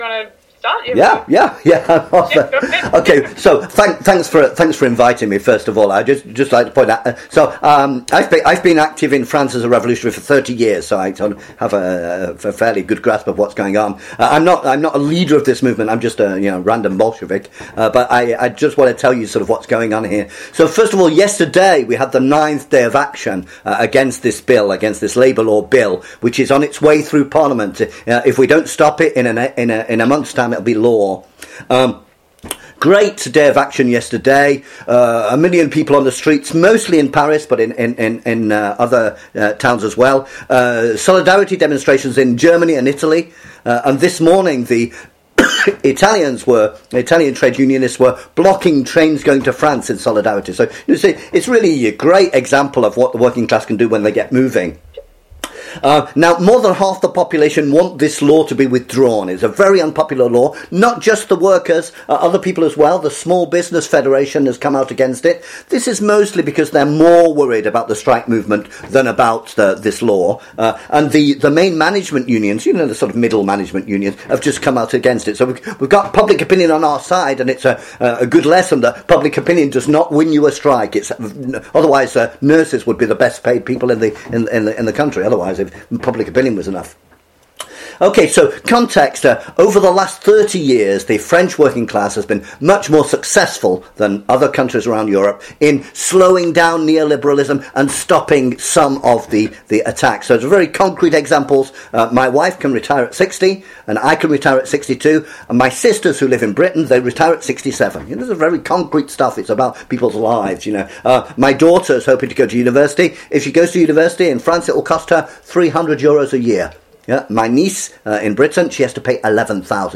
The comrades from Socialist Alternative in Perth and Adelaide, in Australia, kindly invited me to do a meeting for them last Wednesday on the class struggle in France, our chances of winning against the Labour law, and the major political questions the new situation is throwing up.